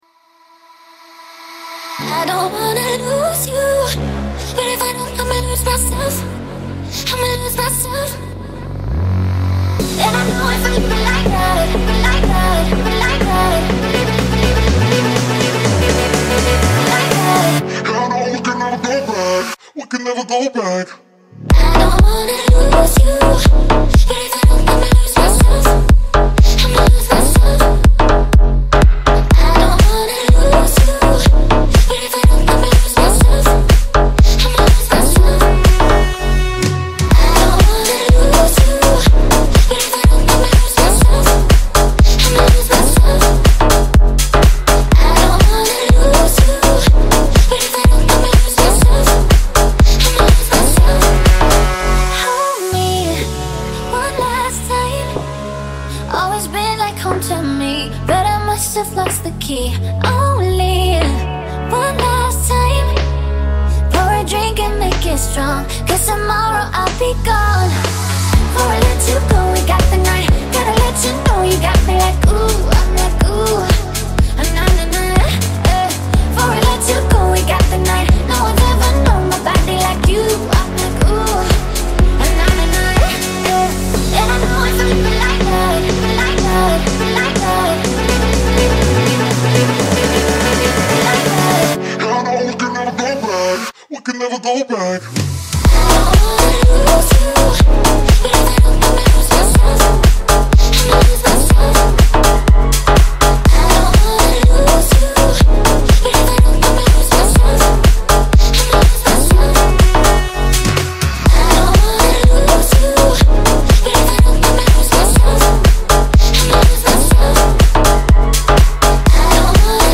BPM123
Audio QualityCut From Video